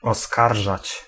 Ääntäminen
IPA : /əˈkjuːz/
IPA : /ə.ˈkjuz/